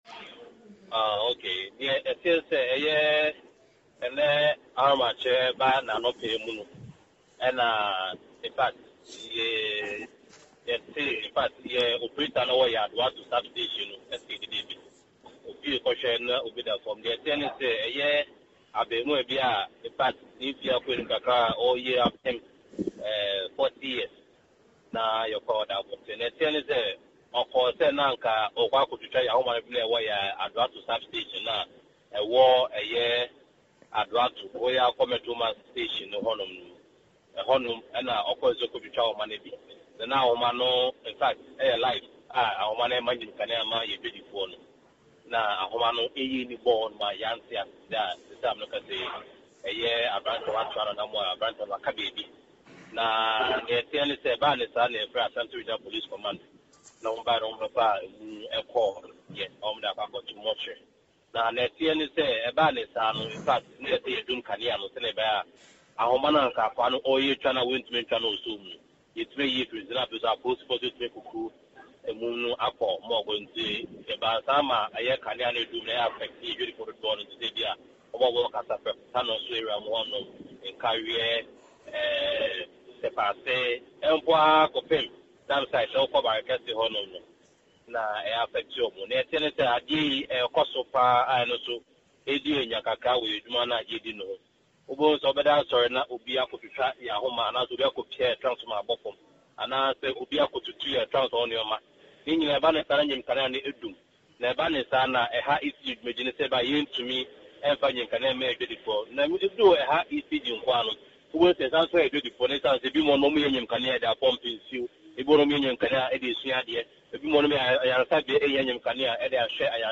Speaking in an exclusive phone interview